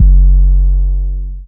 DDW4 808 1.wav